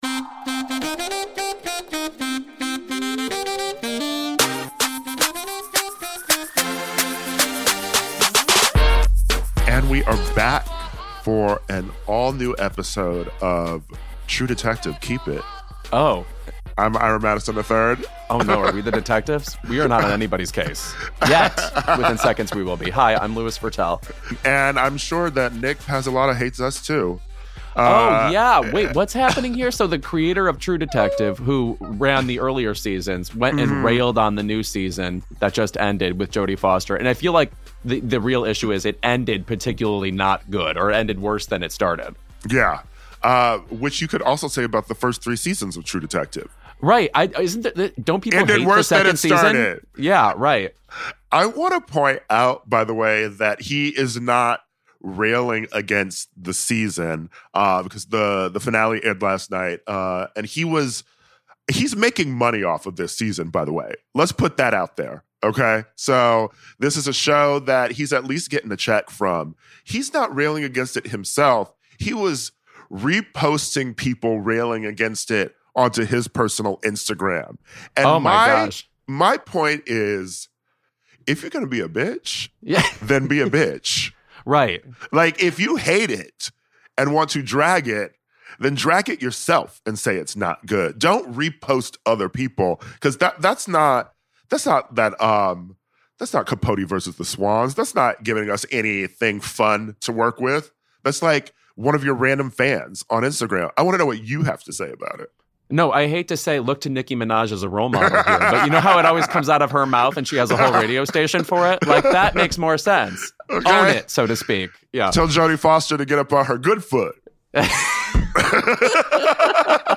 Danielle Brooks joins to discuss playing Sofia in The Color Purple on Broadway and in the new film adaptation, as well as her journey as first-time Oscar nominee. Plus, True Detective season 4, Love Is Blind, and Billie Eilish versus TikTok.